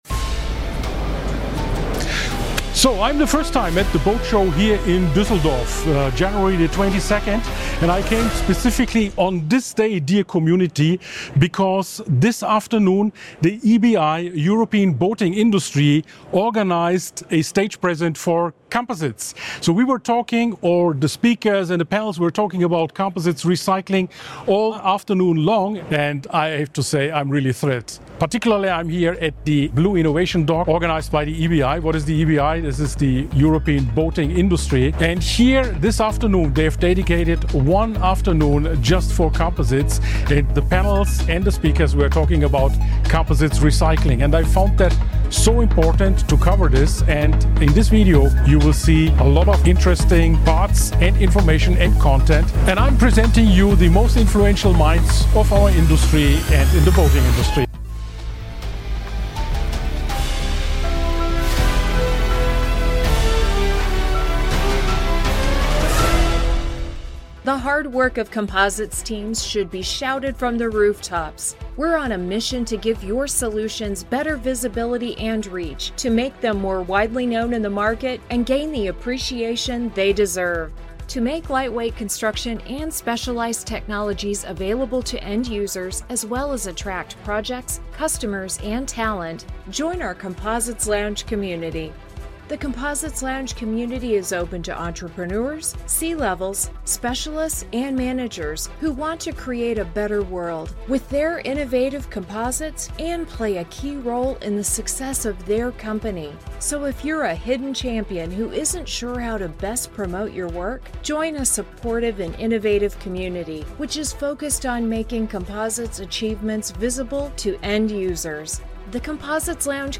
interviews key leaders at the Blue Innovation Dock during boot Düsseldorf 2026. He documents how the European Boating Industry, EuCIA and ECCA are advancing circular composites, scaling recycling technologies and shaping EU policy for sustainable boating.